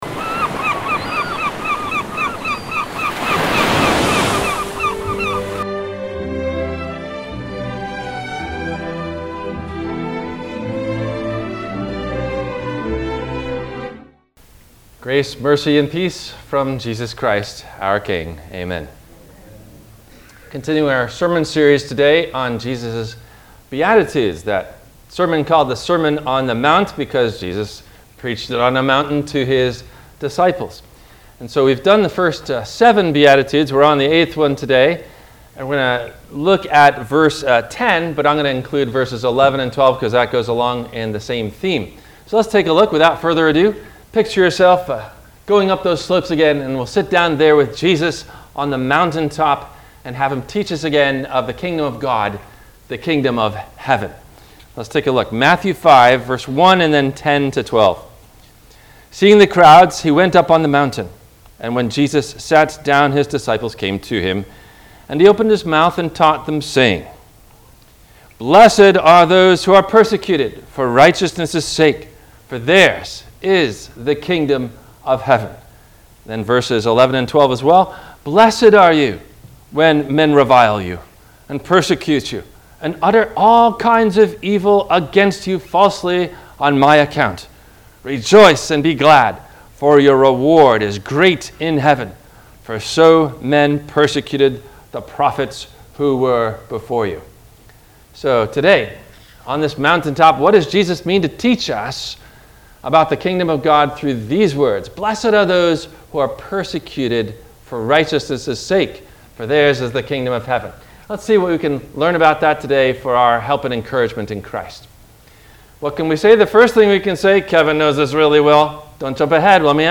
What Did Jesus Mean…Blessed Are You When You Are Persecuted? – WMIE Radio Sermon – September 25 2023